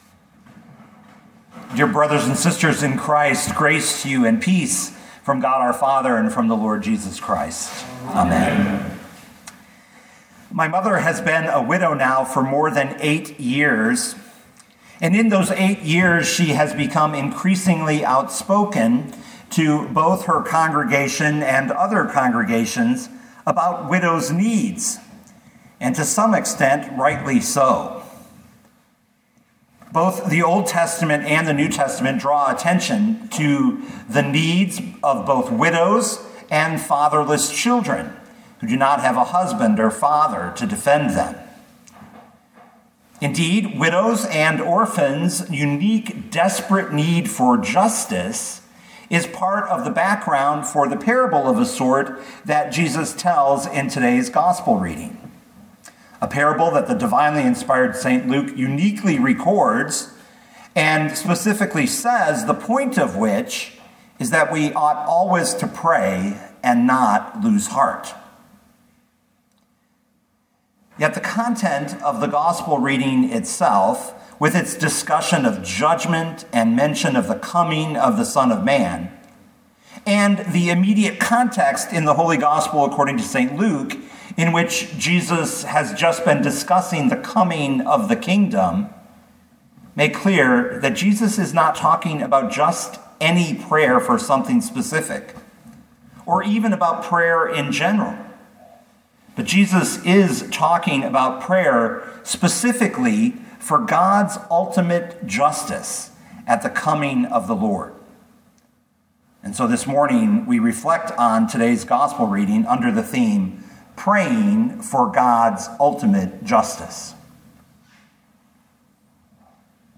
2022 Luke 18:1-8 Listen to the sermon with the player below, or, download the audio.